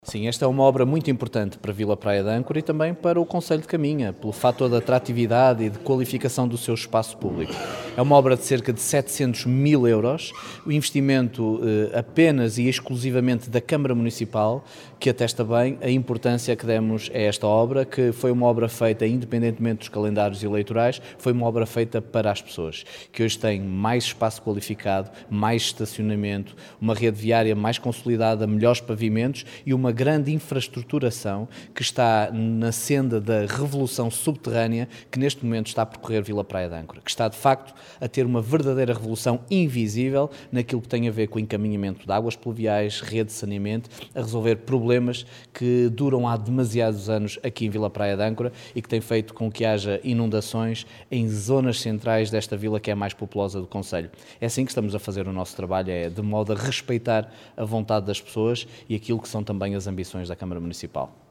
O Secretário de Estado das Autarquias Locais, Carlos Miguel, inaugurou ontem a obra de requalificação do Nó da Erva Verde, em Vila Praia de Âncora.
Miguel Alves, presidente da Câmara de Caminha diz que esta era uma obra muito importante para Vila Praia de Âncora e para o concelho.